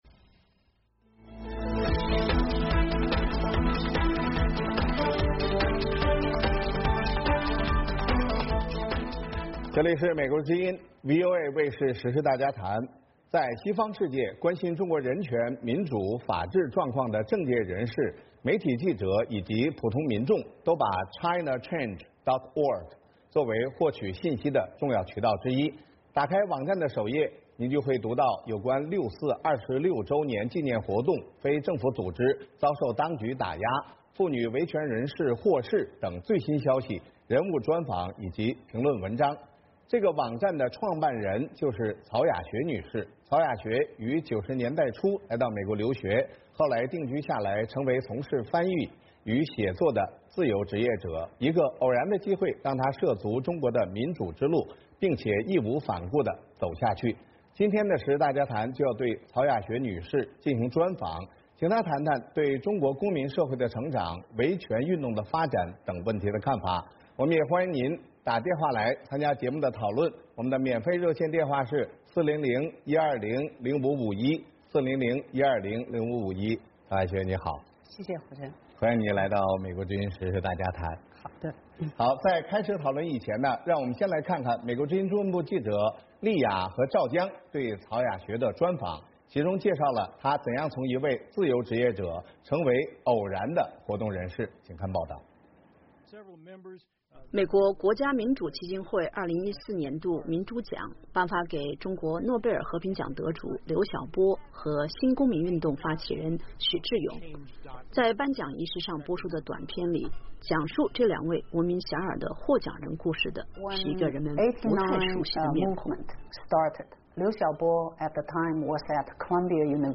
时事大家谈：人物专访：